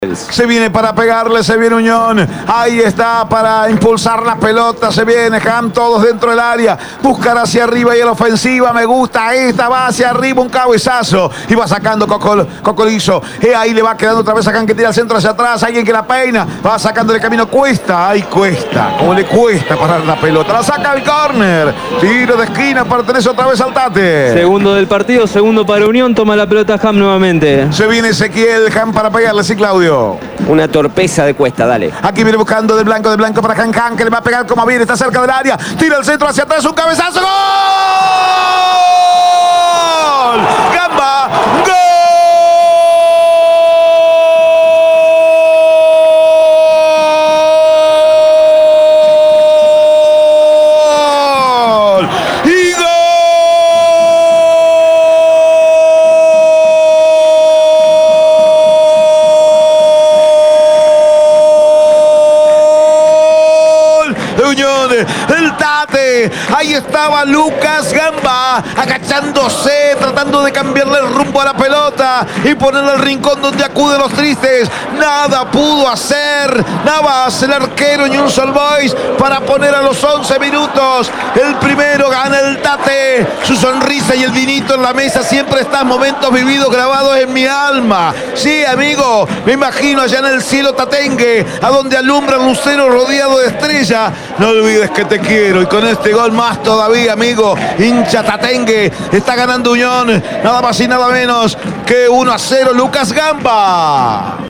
EL GOL DE UNIÓN, EN EL RELATO